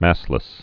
(măslĭs)